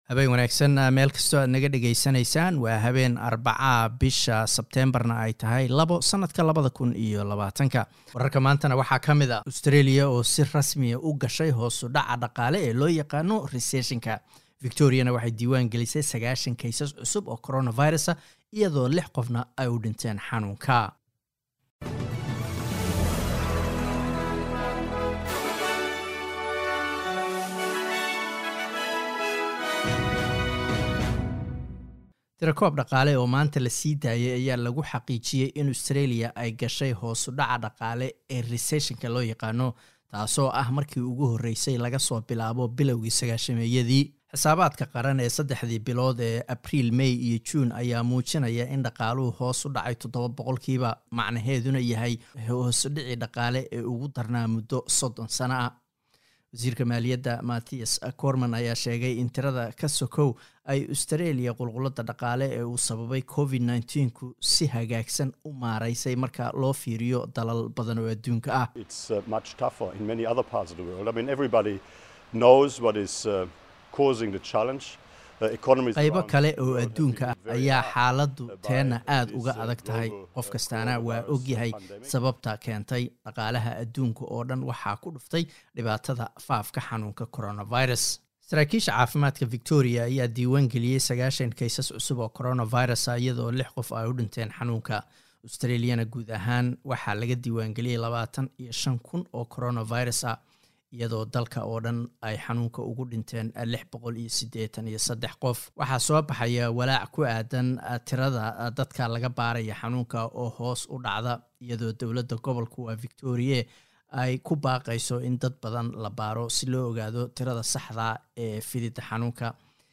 Wararka SBS Somali Arbaco 02 Sep